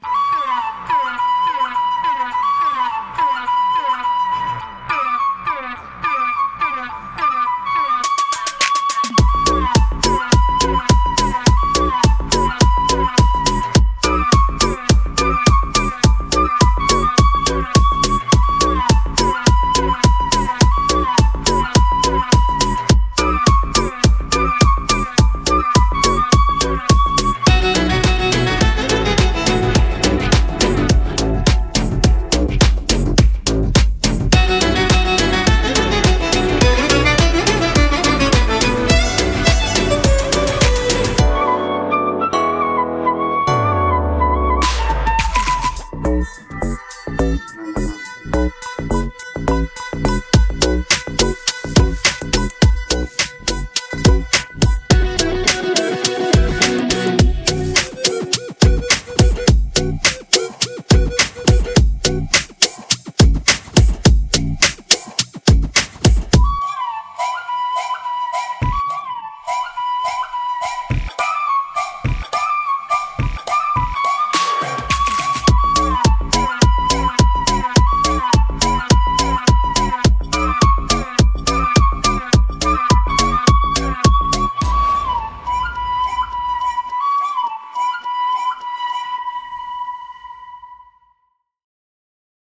without any dialogues